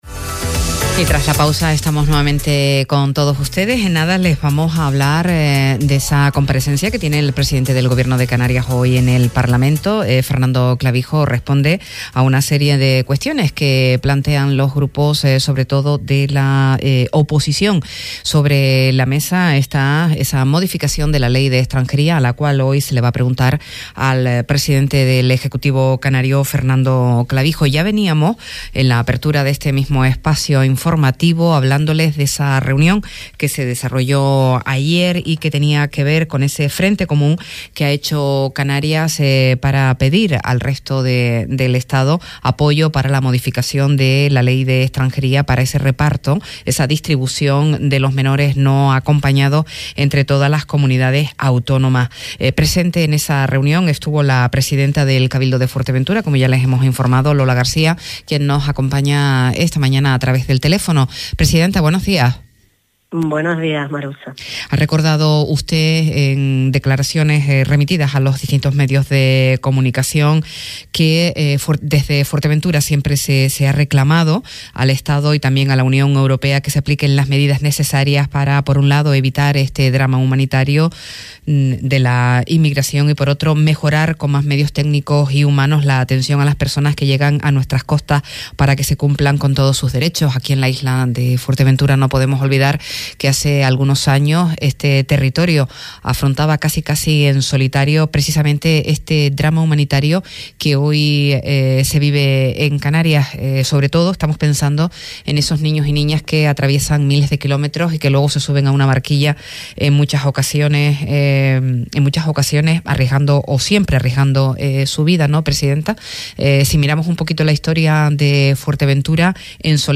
A Primera Hora, entrevista a Lola García, presidenta del Cabildo de Fuerteventura - 09.07.24 - Radio Sintonía
Entrevistas